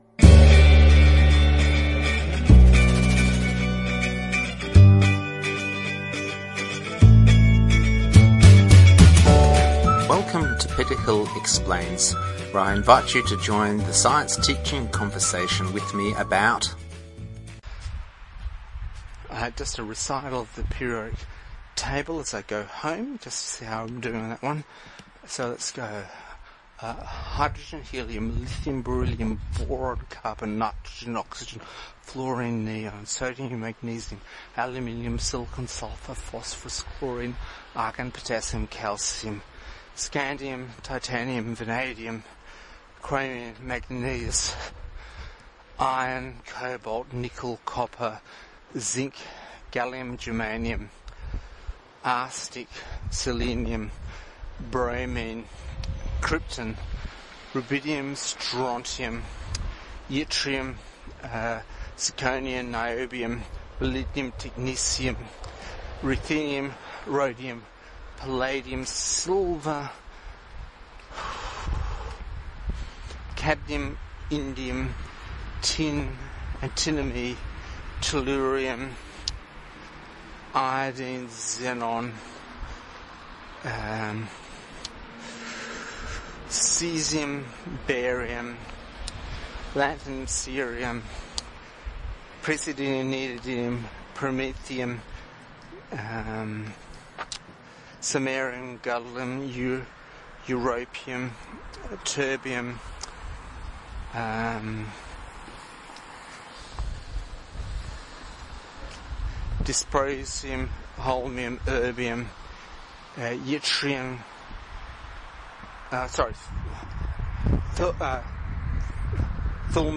Coming together at last, something that is more recital than painful stumbling.